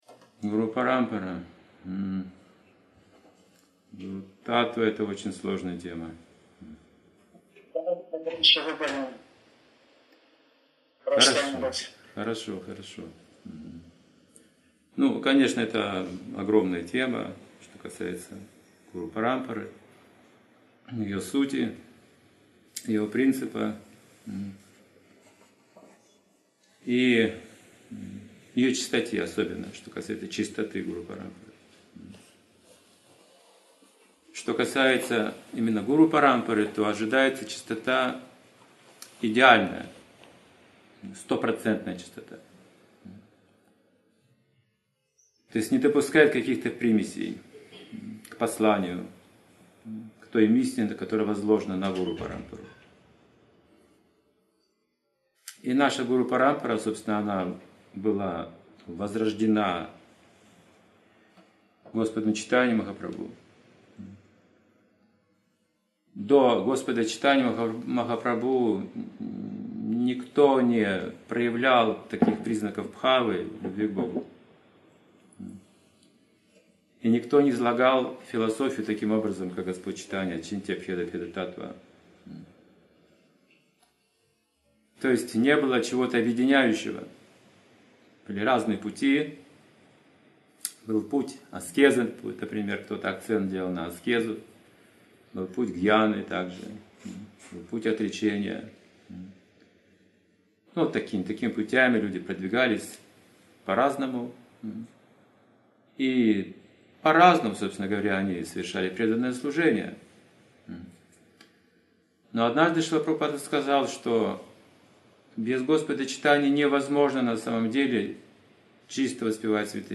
Ответы на вопросы, Цепь духовных учителей протекает через сердце